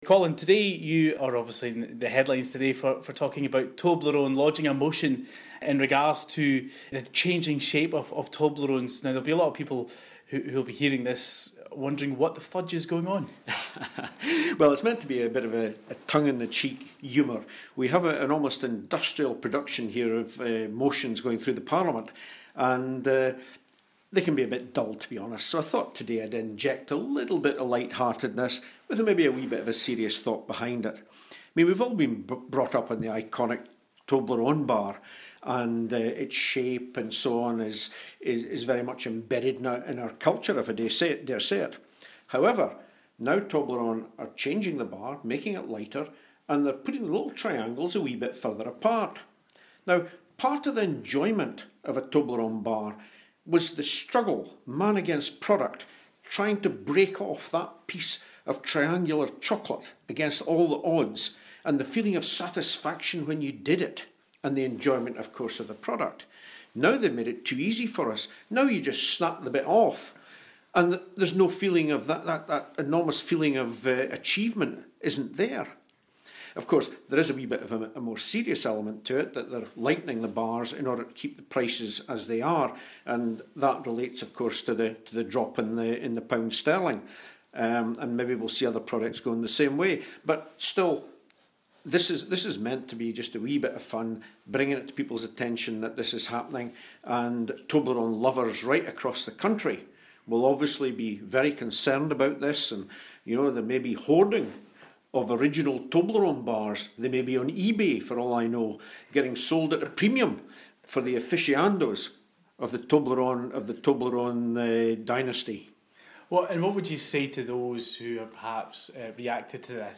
chats with SNP MSP Colin Beattie who this week lodged a motion in the Scottish Parliament over the size of Toblerone - Brexit is getting the blame